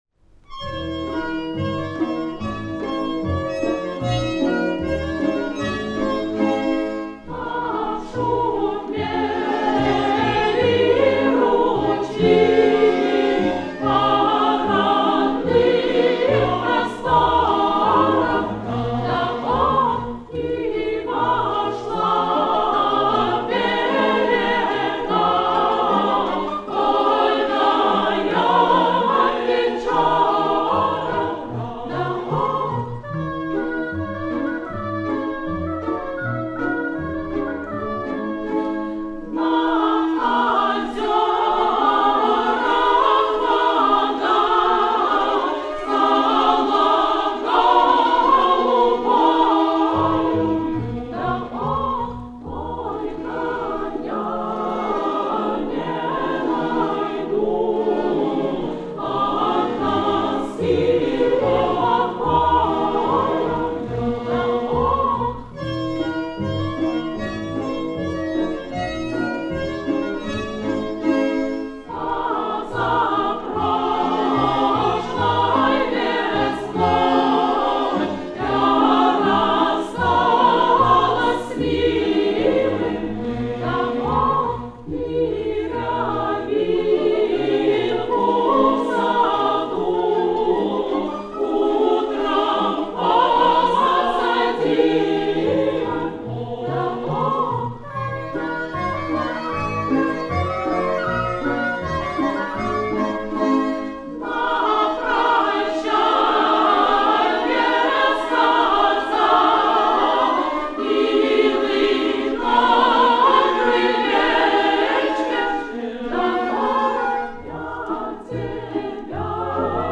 Удорские народные частушки. Запись конца 1940-х - 1950-х гг.